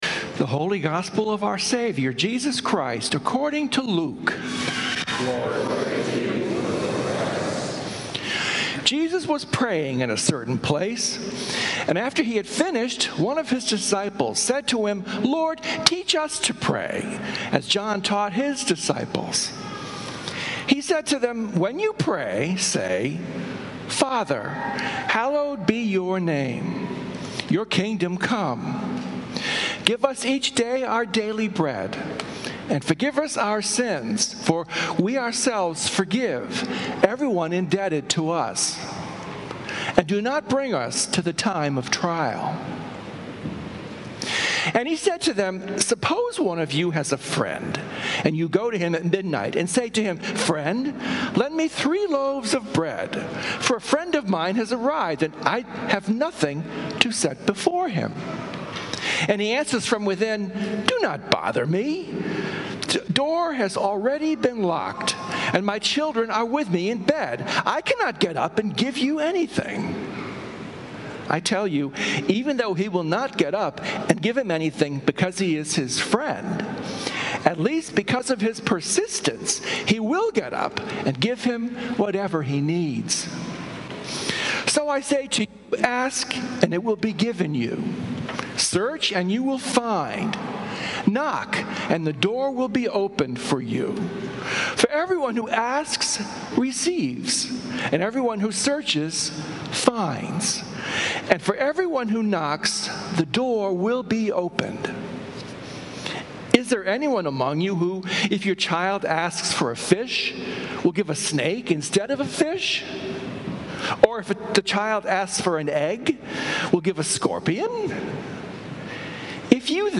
Sermons from St. Columba's in Washington, D.C. Sunday Sermon